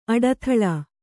♪ aḍathaḷā